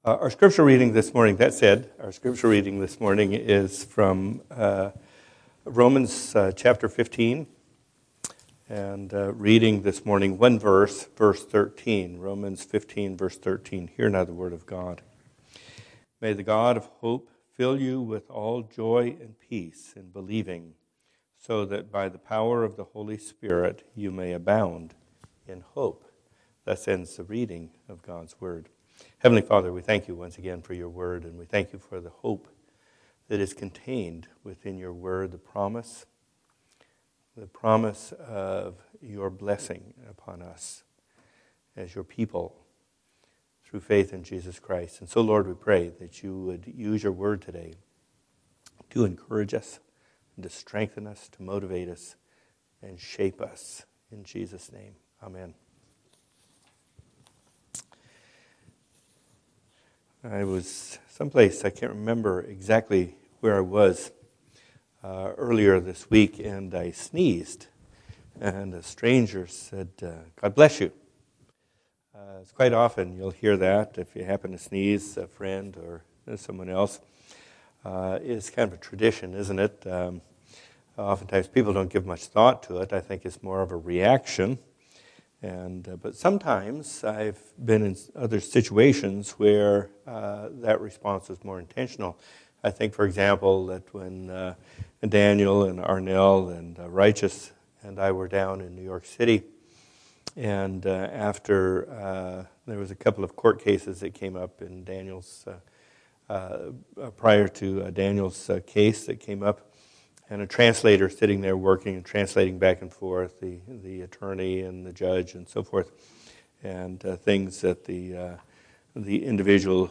Sermons – Grace Church Congregational